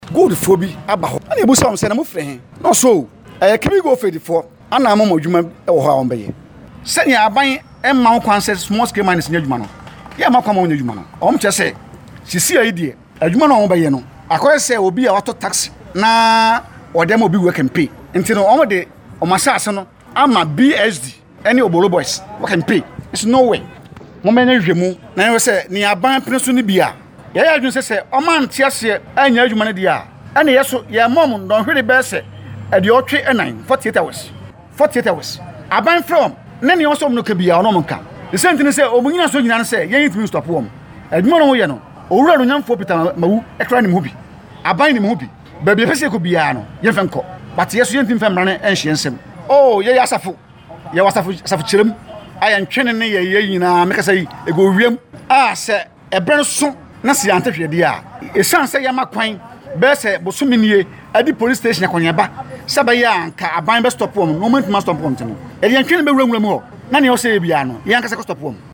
Listen to some members of the group